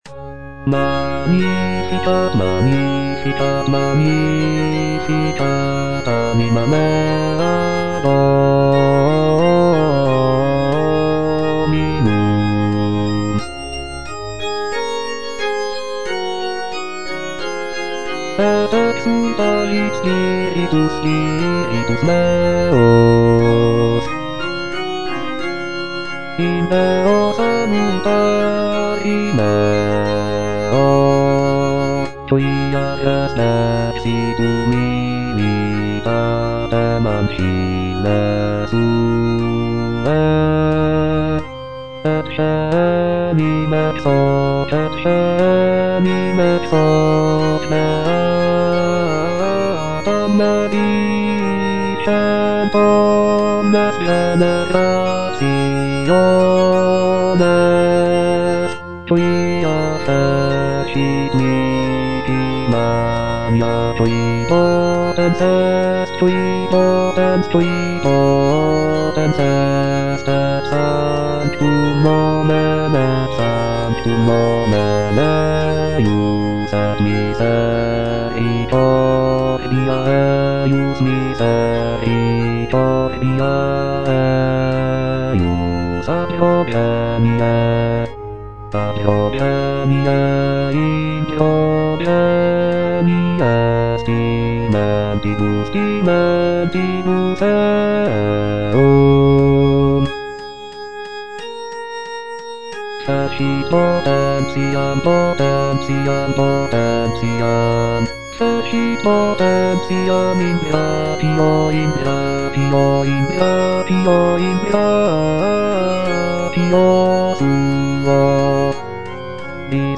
Choralplayer playing Magnificat in D (Edition 2) (A = 415 Hz) by J. Pachelbel based on the edition IMSLP #439390
J. PACHELBEL - MAGNIFICAT IN D (EDITION 2) (A = 415 Hz) Bass (Voice with metronome) Ads stop: